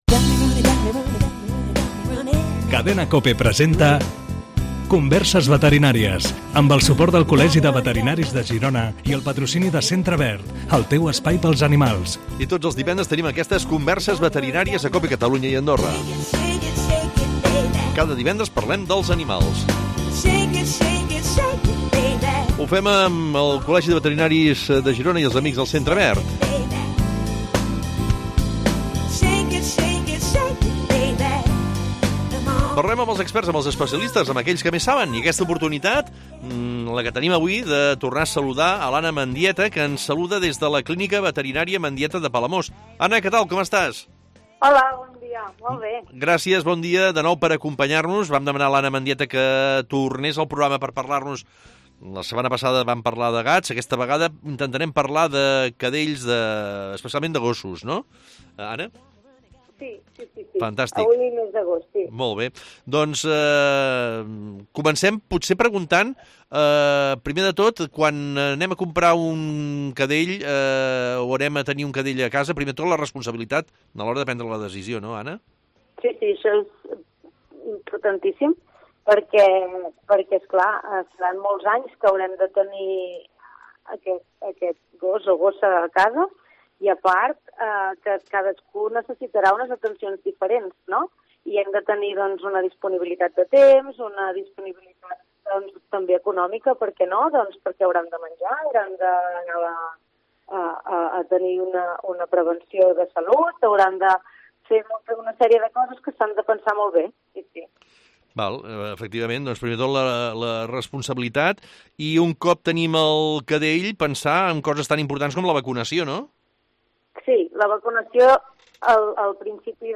Els oients tenen també puntualment la possibilitat de fer les consultes de les seves mascotes amb els nostres veterinaris. Es contesta per ràdio a les preguntes de propietaris de gossos i gats.